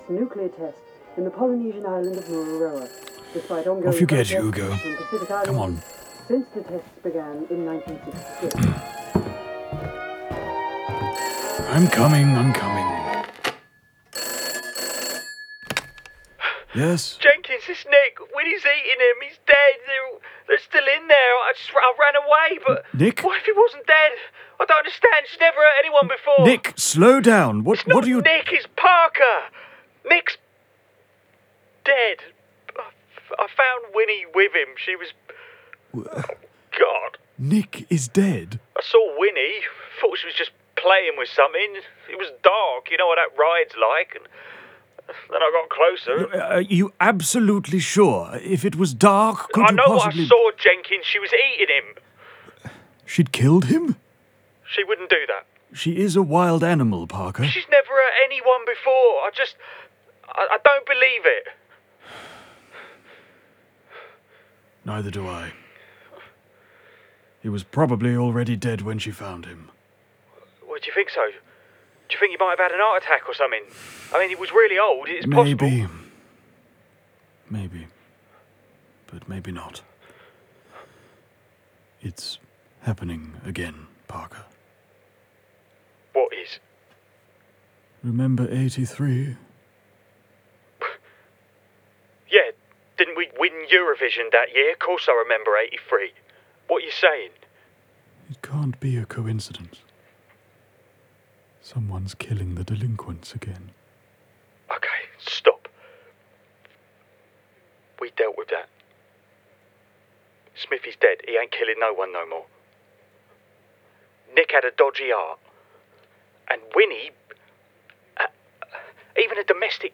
• Male
• Standard English R P
Mockery Manor - Drama Podcast, 2 characters
Mockery-Manor-2-Two-Characters.mp3